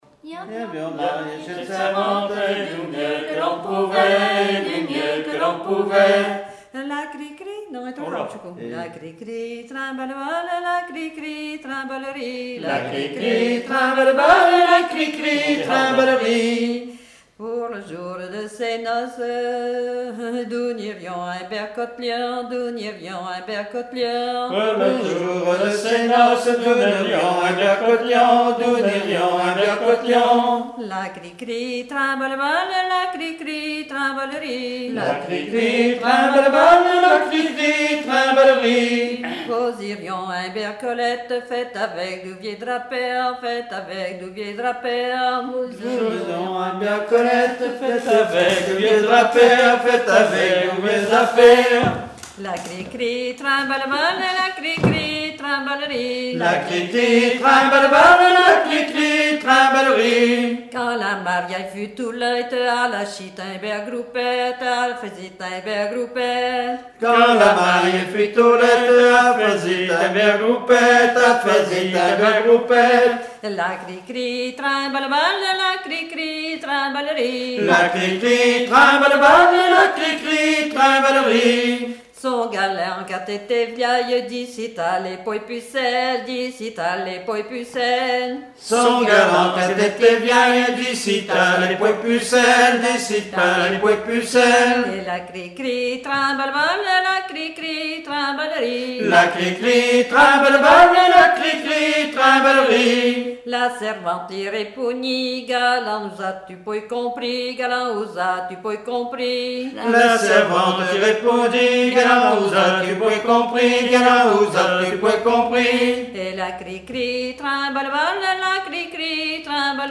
danse : ronde : grand'danse
Genre laisse
Répertoire de chansons traditionnelles et populaires